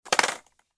جلوه های صوتی
دانلود صدای اسکیت 4 از ساعد نیوز با لینک مستقیم و کیفیت بالا